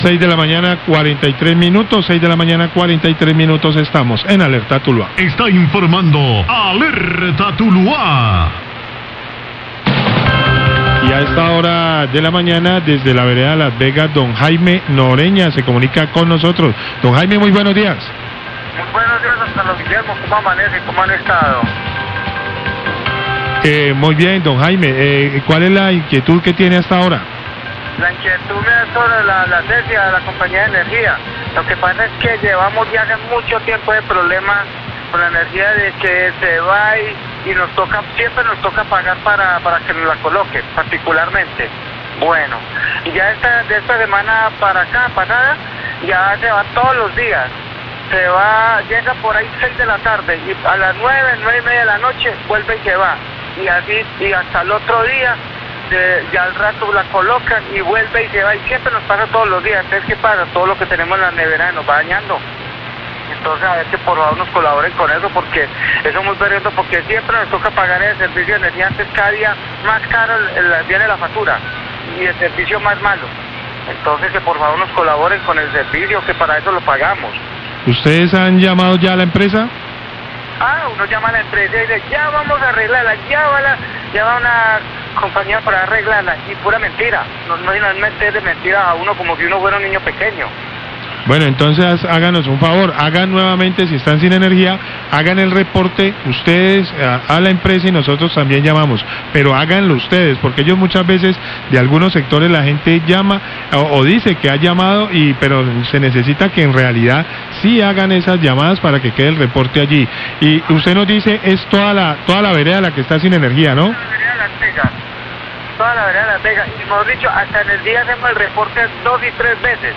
Radio
queja oyentes